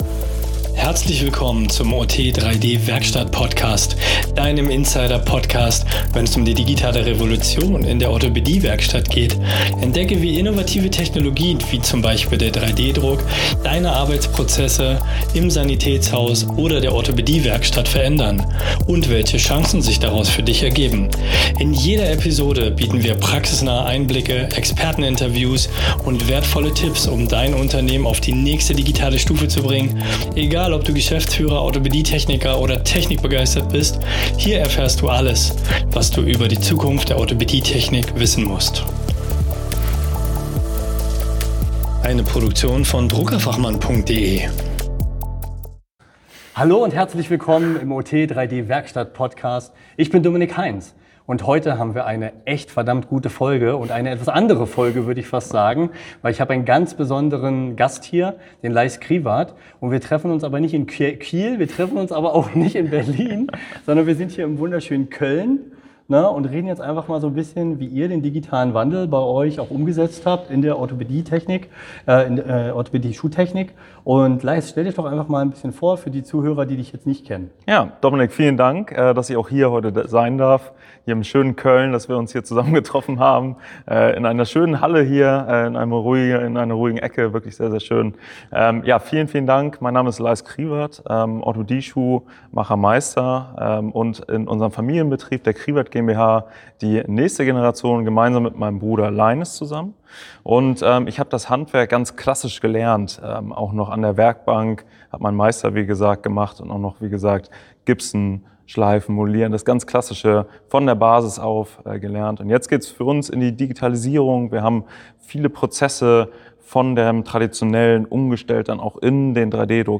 Interview
direkt von der OST Köln 2025 über den Weg von der klassischen Werkbank zur digitalen High-End-Versorgung.